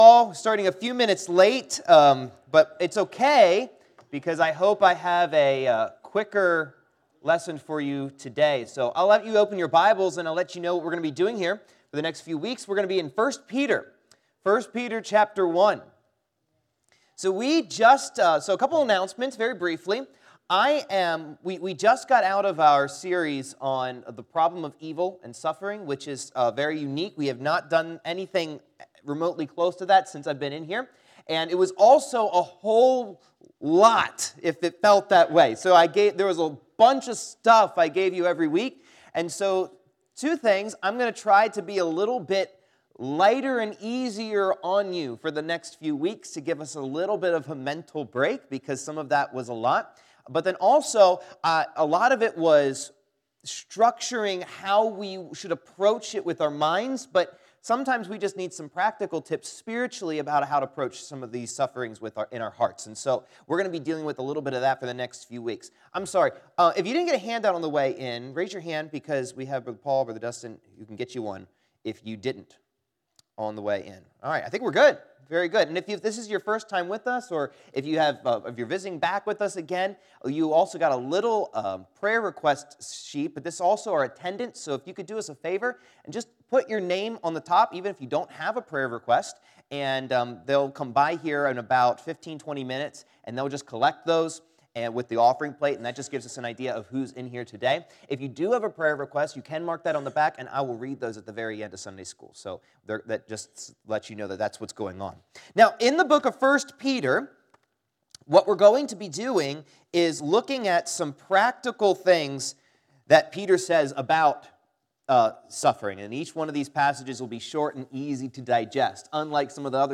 Auditorium Bible Class « A Resurrected Life The Truth About Biblical Predestination